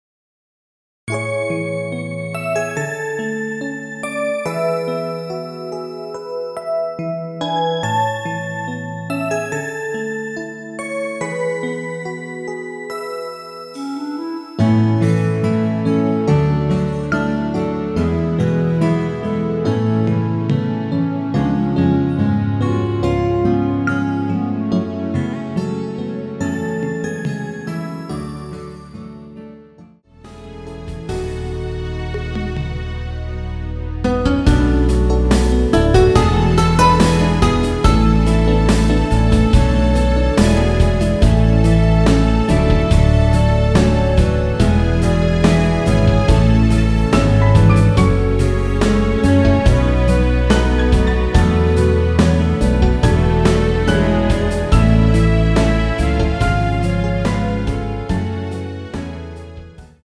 멜로디 MR이라고 합니다.
앞부분30초, 뒷부분30초씩 편집해서 올려 드리고 있습니다.